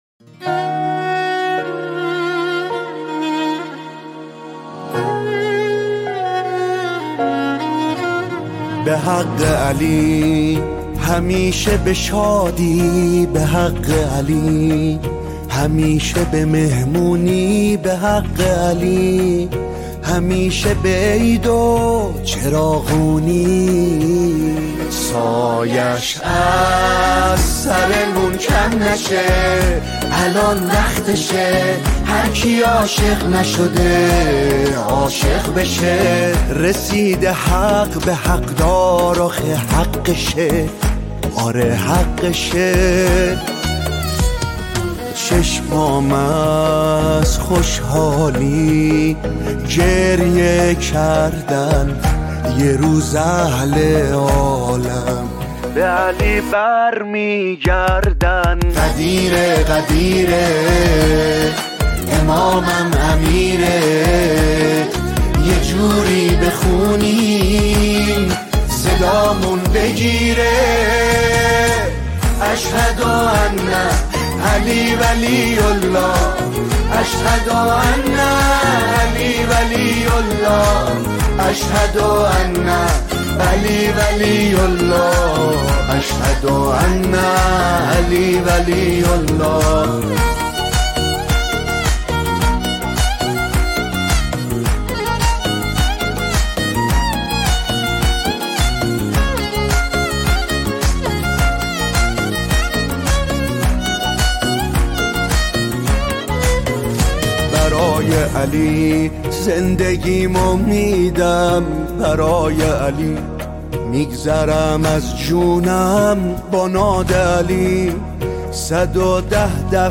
نماهنگ